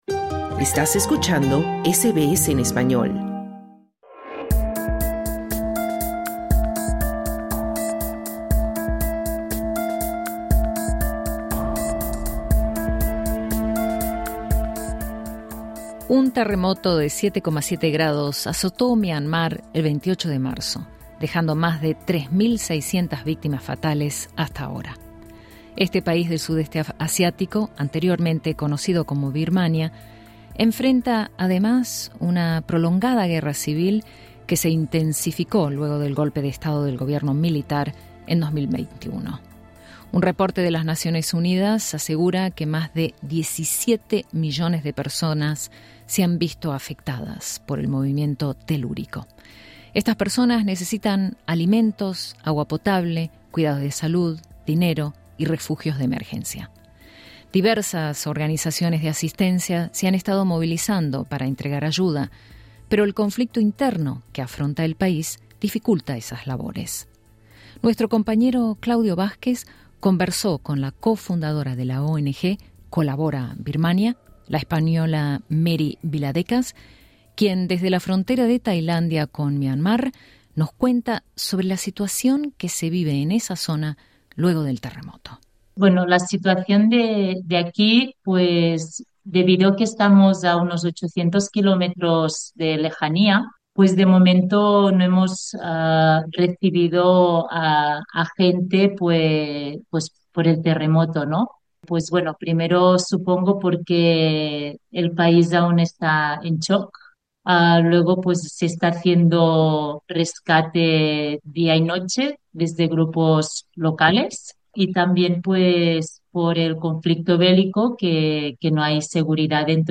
Cooperante española narra desde la frontera de Myanmar las consecuencias del devastador terremoto